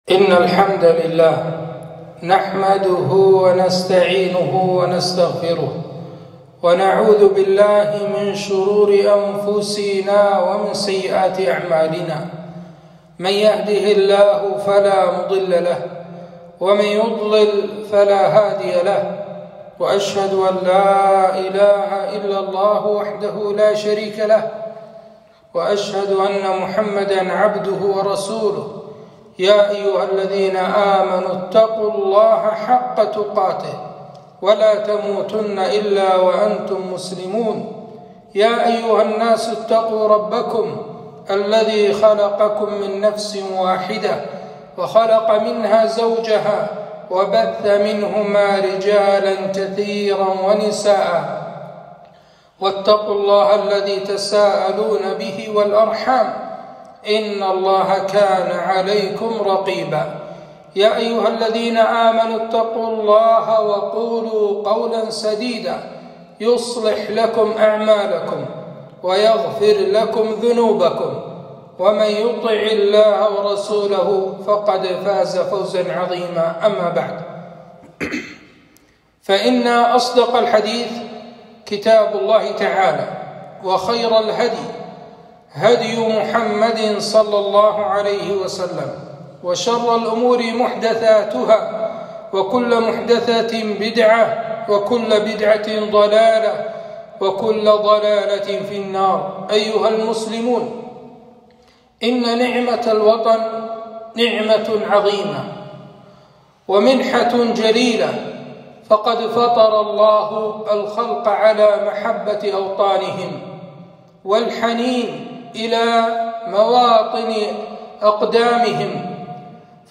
خطبة - نعمة الوطن